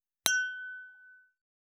293チーン,カラン,キン,コーン,チリリン,カチン,チャリーン,クラン,カチャン,クリン,シャリン,チキン,コチン,カチコチ,チリチリ,シャキン,カランコロン,パリーン,ポリン,トリン,
コップ効果音厨房/台所/レストラン/kitchen食器
コップ